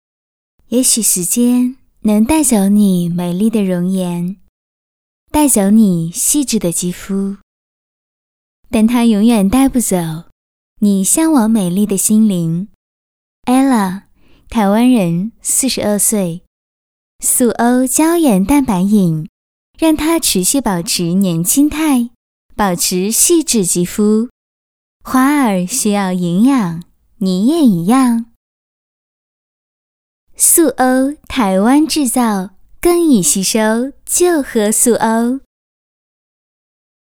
【台湾腔】台湾制造